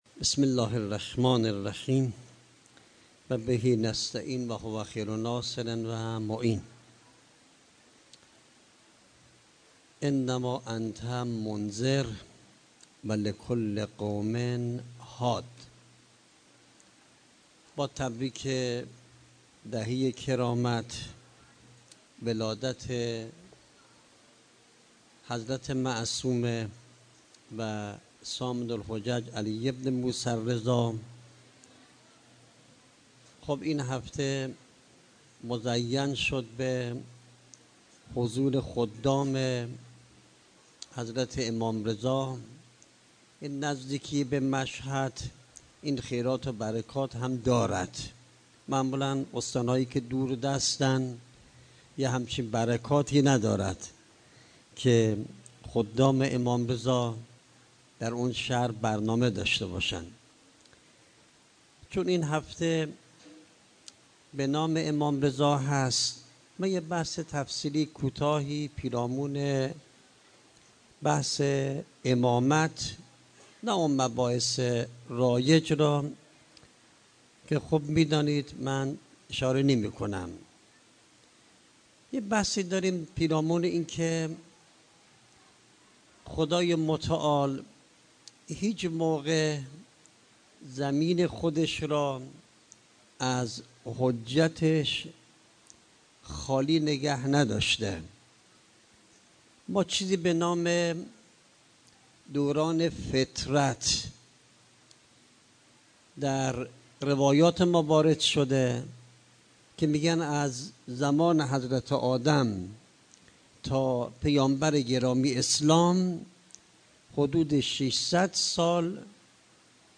طرح مباحث تفسیری